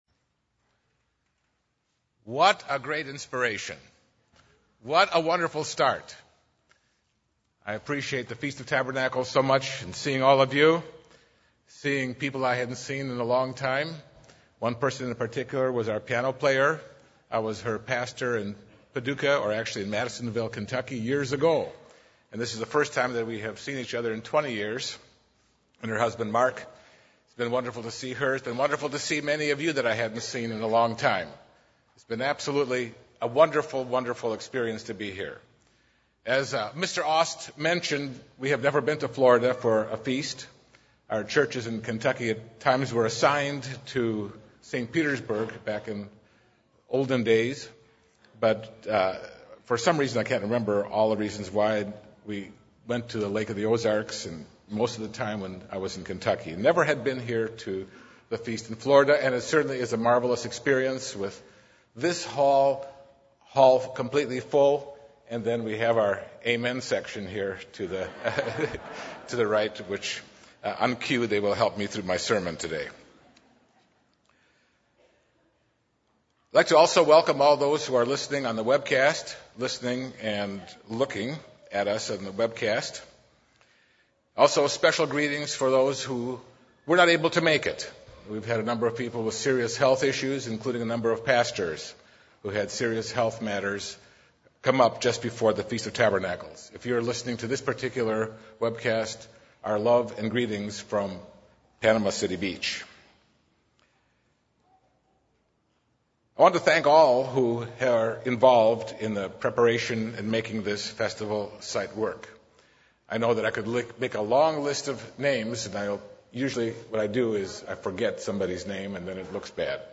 This sermon was given at the Panama City Beach, Florida 2014 Feast site.